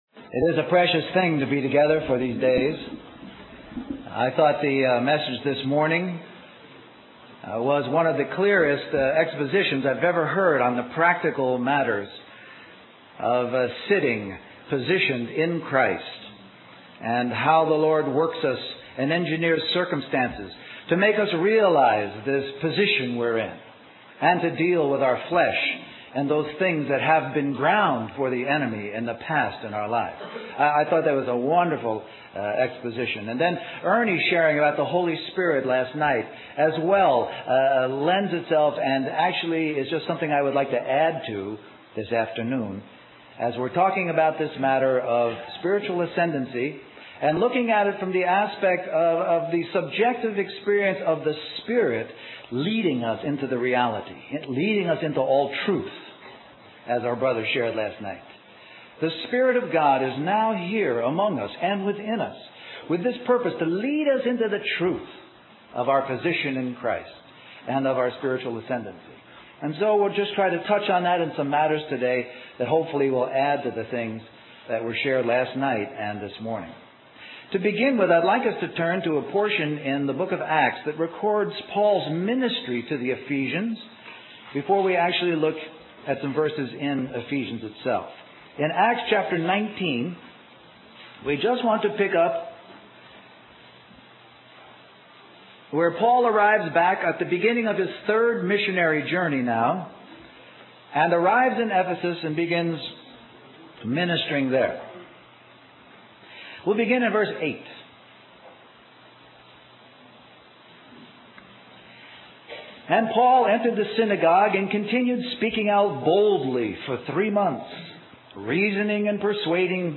In this sermon, the speaker shares a personal experience of encountering a woman who questioned his presence as a man of God.